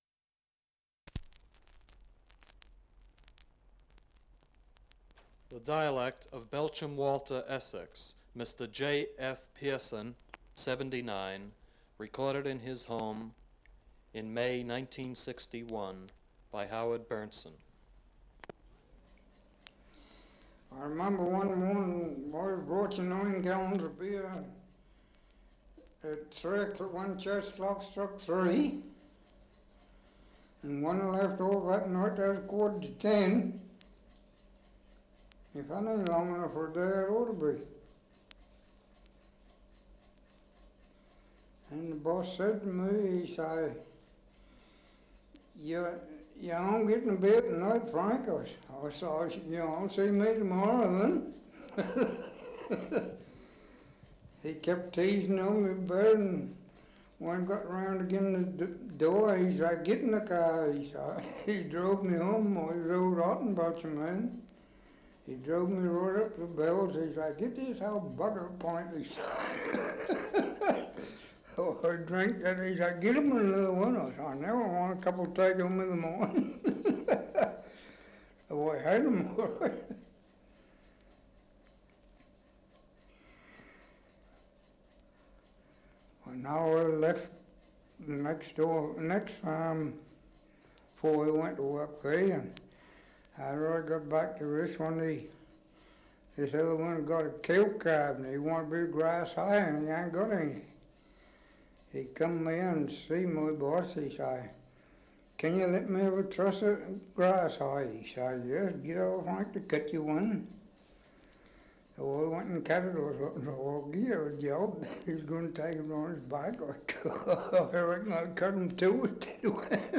2 - Survey of English Dialects recording in Great Chesterford, Essex. Survey of English Dialects recording in Belchamp Walter, Essex
78 r.p.m., cellulose nitrate on aluminium